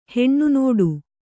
♪ heṇṇu nōḍu